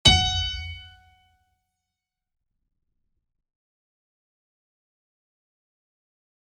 piano-sounds-dev
HardAndToughPiano